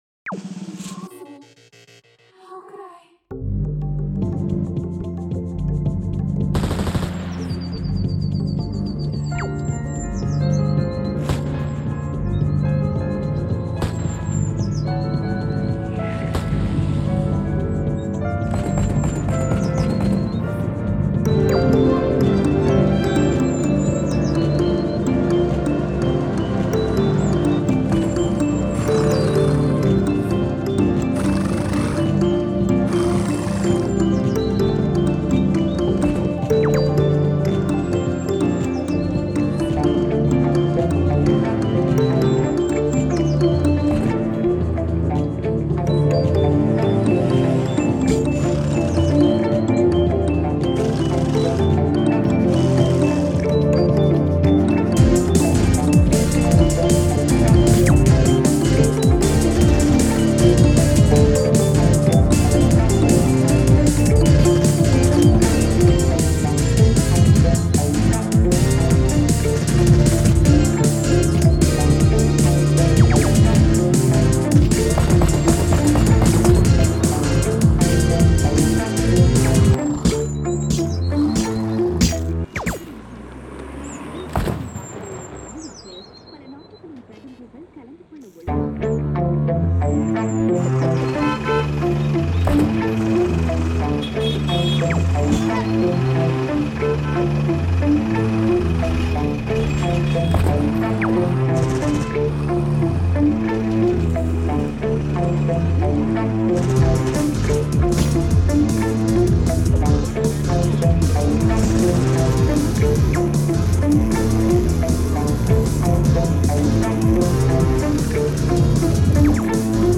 This is an experimental sound collage automatically generated using Freesound Timel